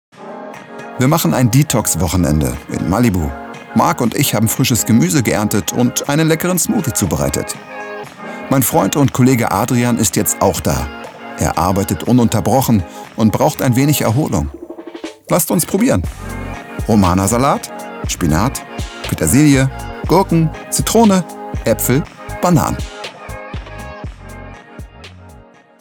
dunkel, sonor, souverän, plakativ
Mittel plus (35-65)
Voice Over 01 - Detox
Comment (Kommentar), Doku, Off, Overlay